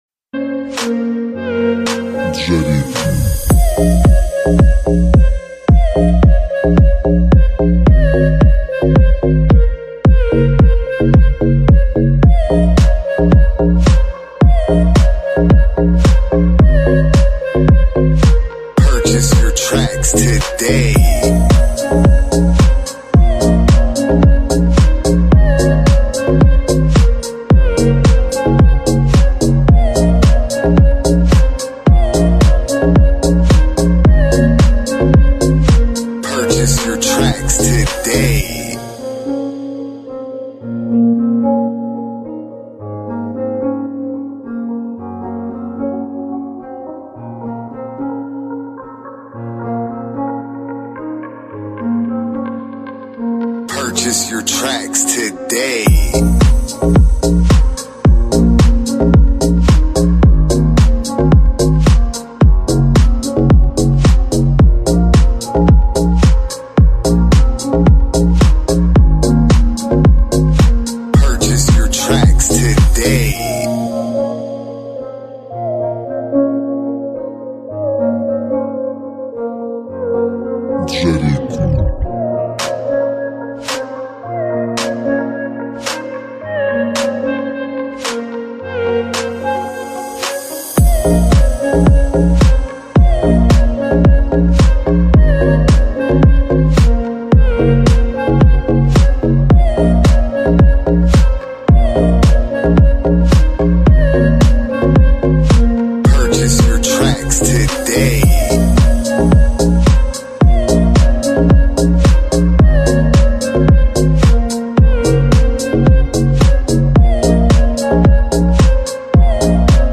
Mama cat takecare of her sound effects free download